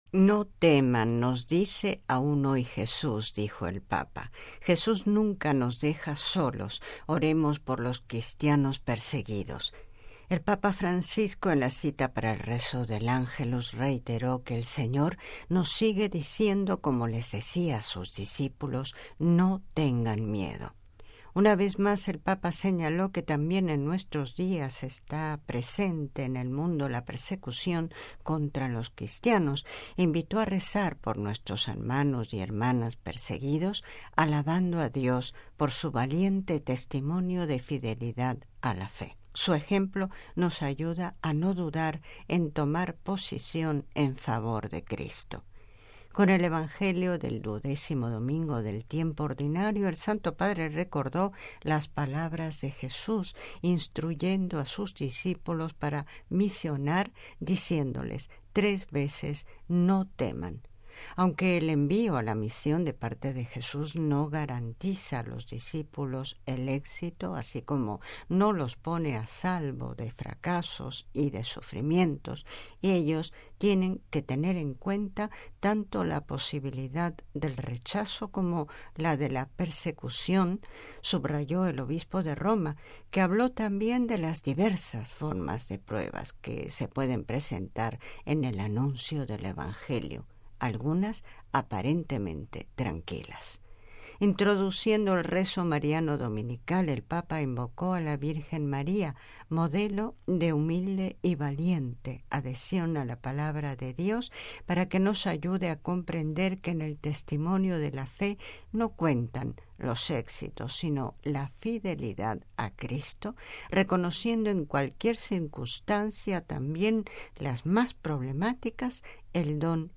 (RV).- El Papa Francisco, en la cita para el rezo del Ángelus, reiteró que el Señor nos sigue diciendo, como les decía a sus discípulos: ¡No tengan miedo!